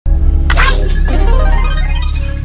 Death Stranding Like Notification Sound